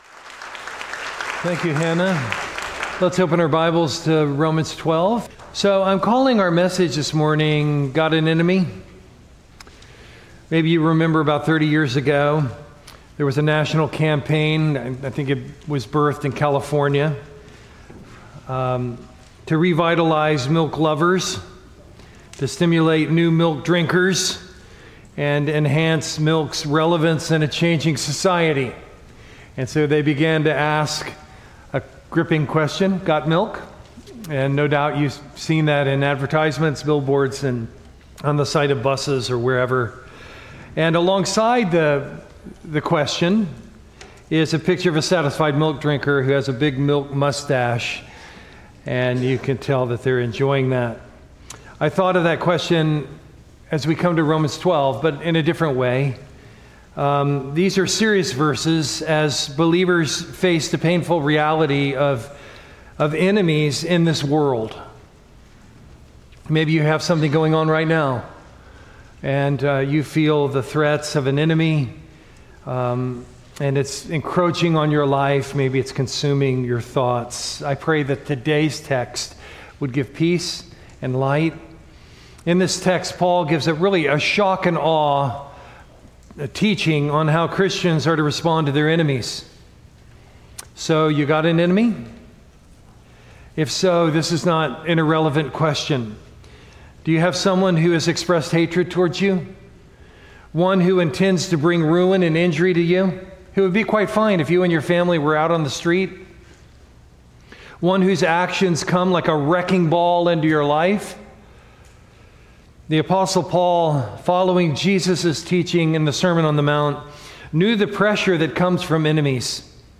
Sermon content from First Baptist Church Gonzales, LA